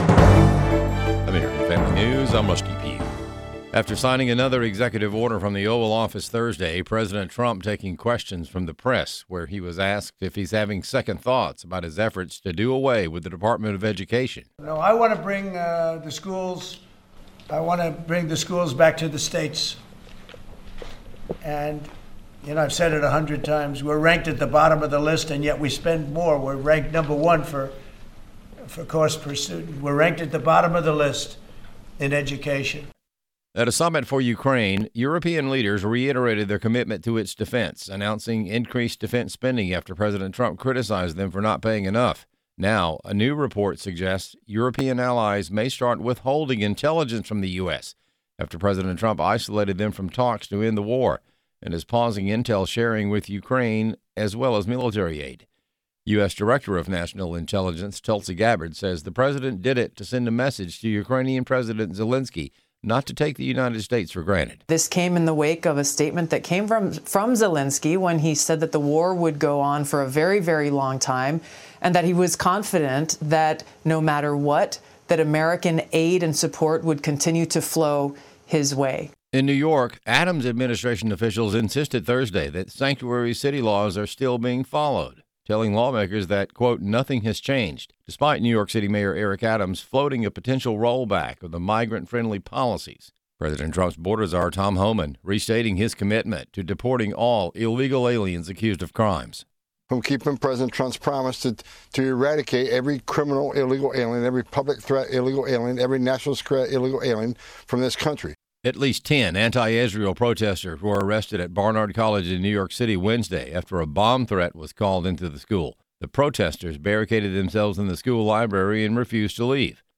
American Family News Newscast